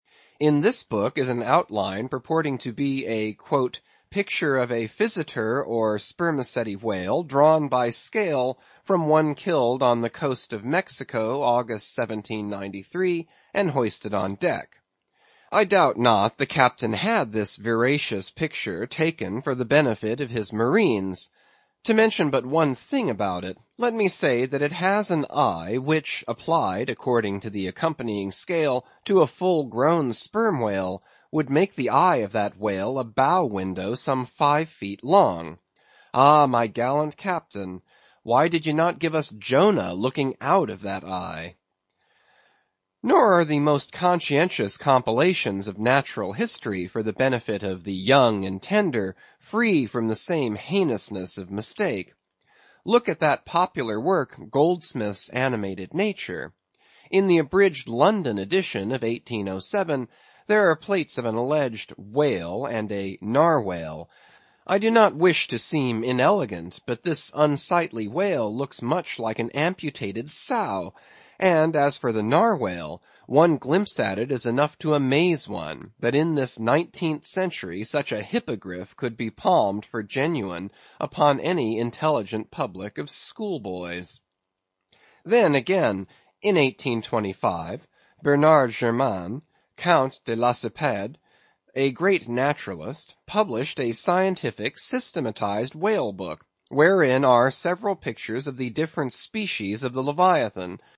英语听书《白鲸记》第566期 听力文件下载—在线英语听力室